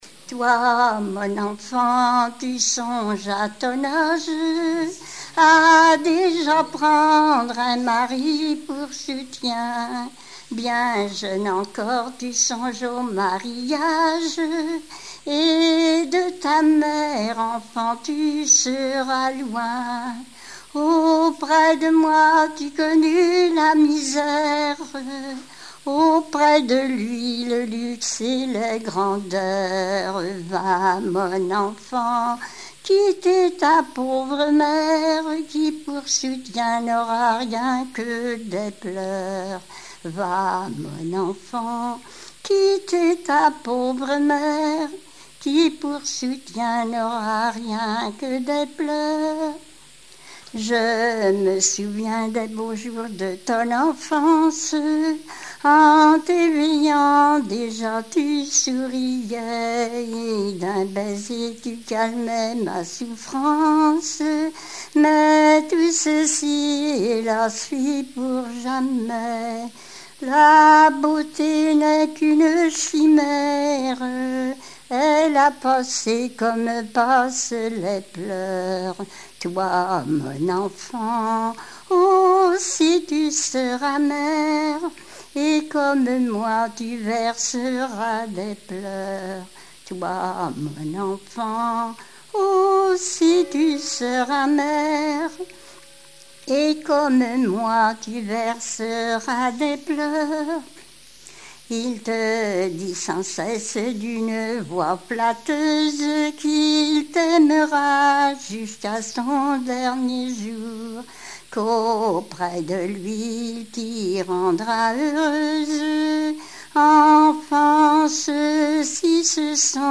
Genre strophique
Répertoire de chansons populaires et traditionnelles